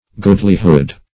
Search Result for " goodlyhood" : The Collaborative International Dictionary of English v.0.48: Goodlyhead \Good"ly*head\, Goodlyhood \Good"ly*hood\n. Goodness; grace; goodliness.